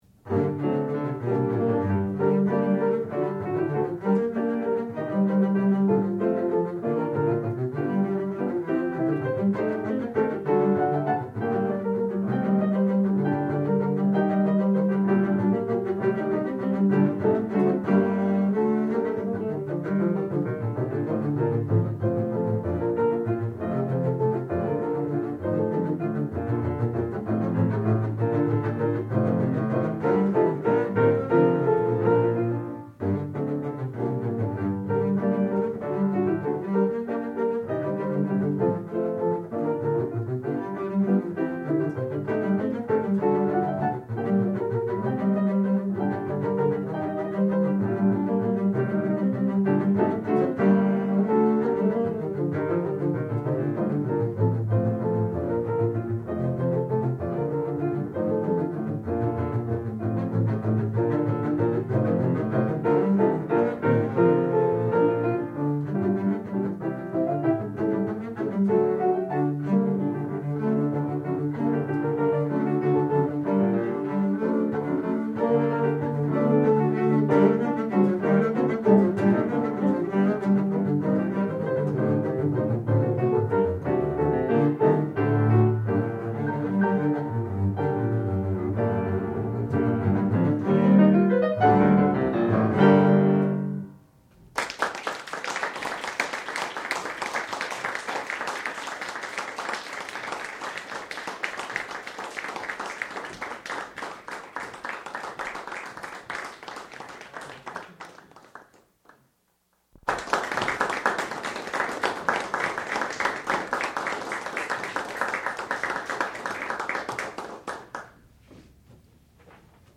sound recording-musical
classical music
piano
double bass
Qualifying Recital